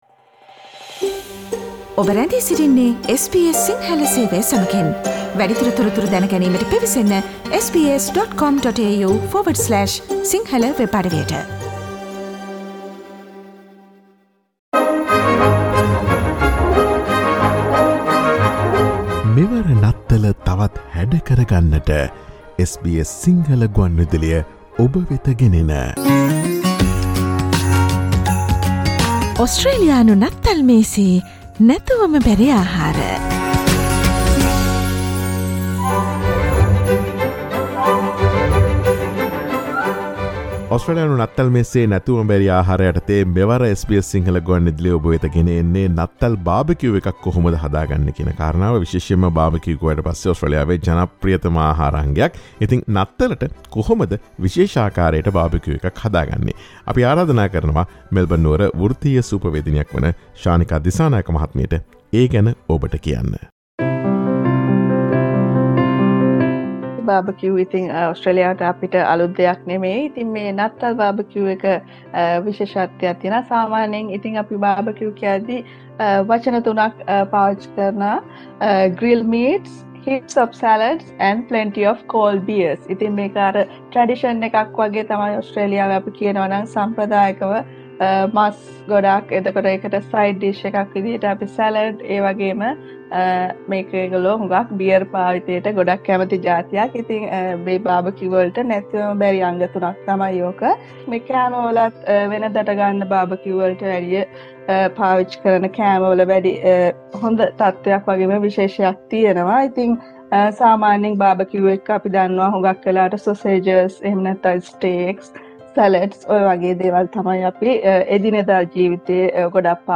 a professional Chef from Melbourne talks to us.